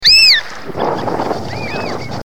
American Oystercatcher call
Category: Animals/Nature   Right: Personal